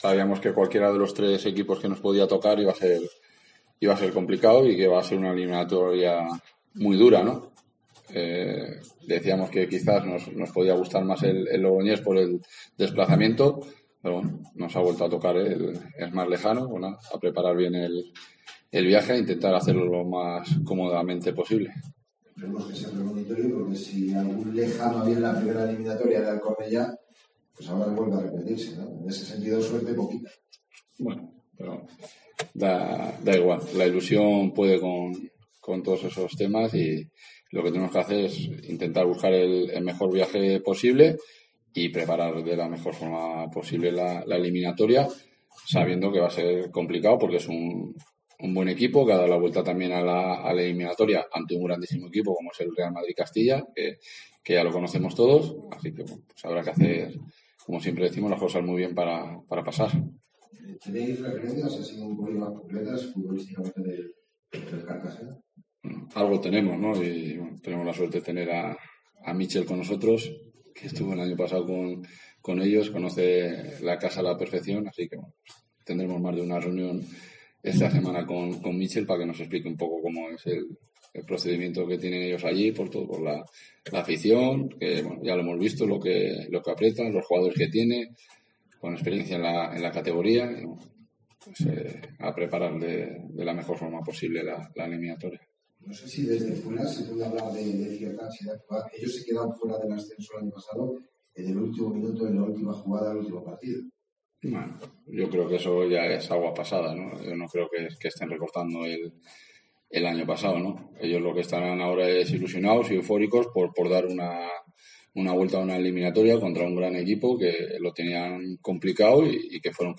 Escucha aquí las palabras del míster de la Deportiva Ponferradina, Jon Pérez Bolo, tras conocer que el Cartagena será el rival en la segunda eliminatoria
El entrenador de la Deportiva Ponferradina, Jon Pérez Bolo, ha valorado ya en la sala de prensa de El Toralín, al rival que tendrán los bercianos en la segunda eliminatoria de la fase de ascenso y que será el Cartagena.